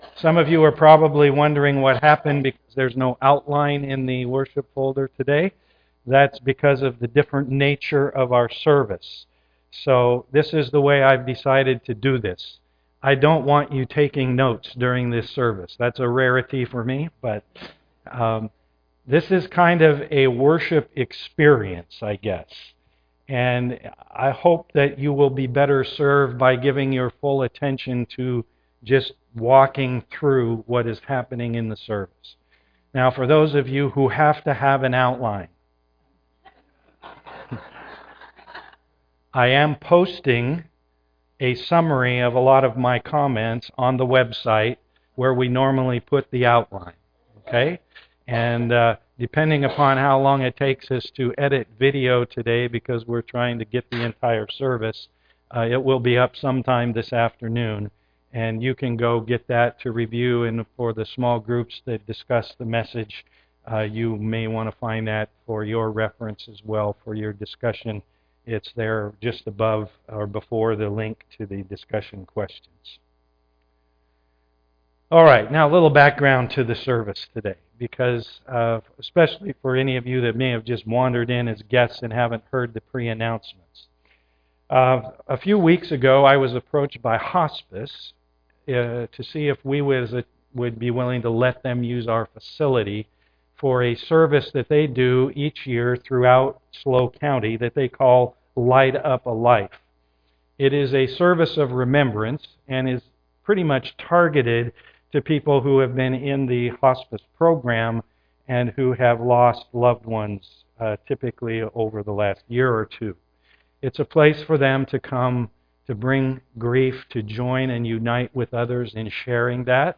Remembrance Service
Today's service is unique as we seek to remember our loved ones and church family whom God has already called to His presence. Our recording will include the entire service, though the quality may be a bit uneven.
[unfortunately, it looks like our camera cut off unexpectedly so the conclusion of the service, including communion, is not available except on the audio.] The music did not come across well to the audio, so it has been deleted.